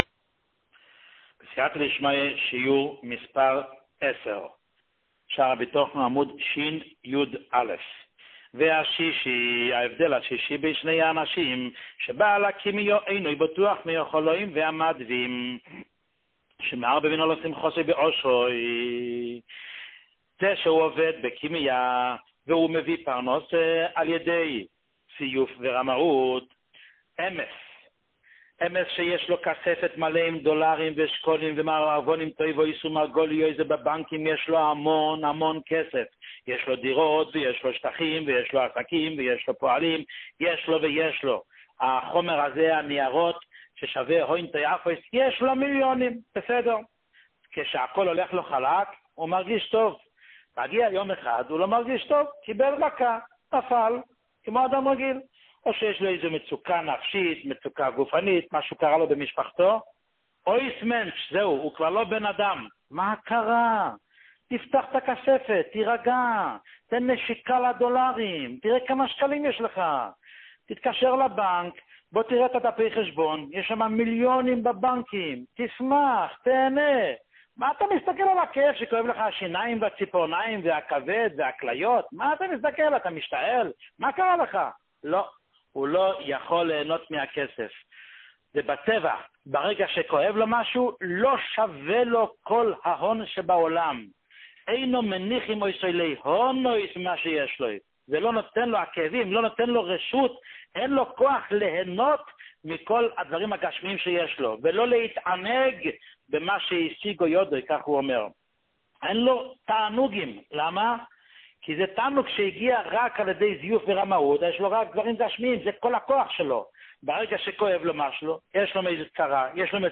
שיעור מספר 10